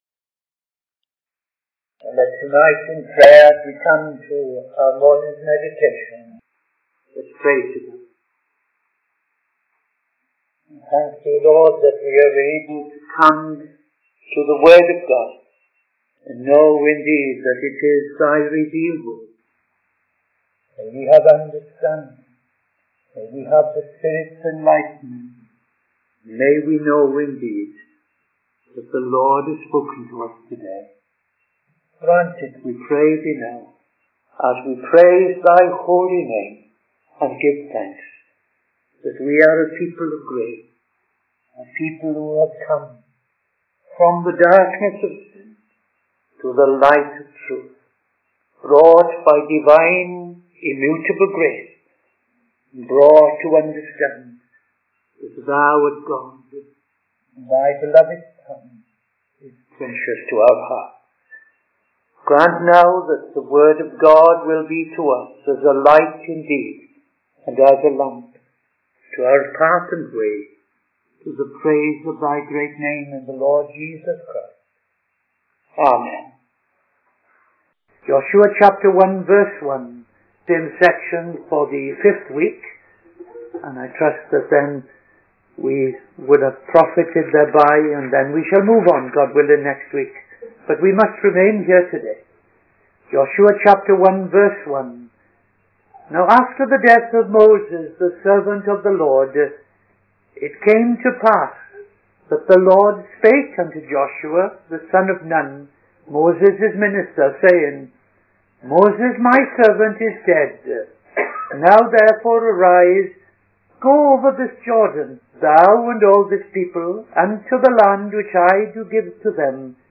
Midday Sermon 28th September 2025